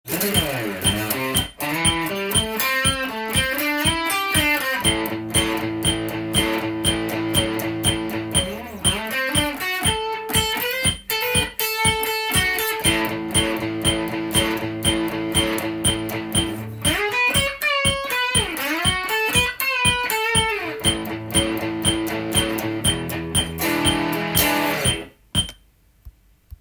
ブルースで２バース　特典もあり
ブルースで２バースという２小節ずつギターソロと
伴奏を弾く練習をします。